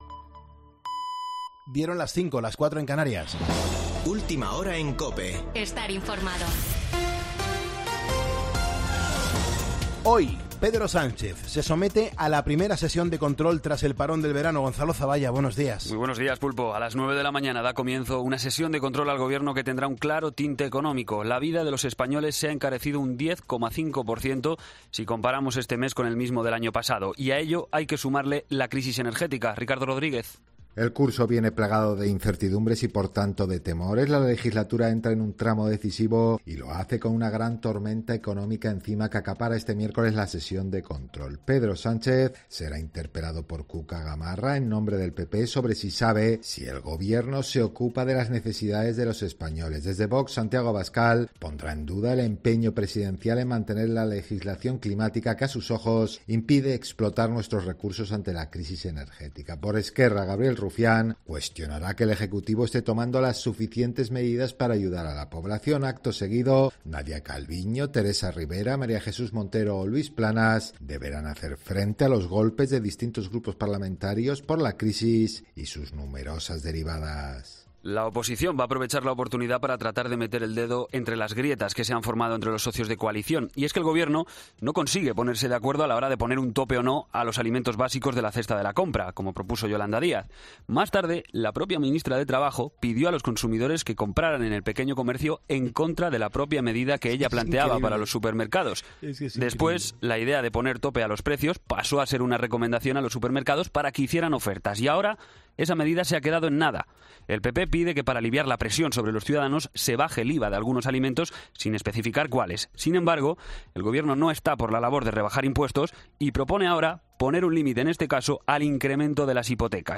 Boletín de noticias COPE del 14 de septiembre a las 05:00 horas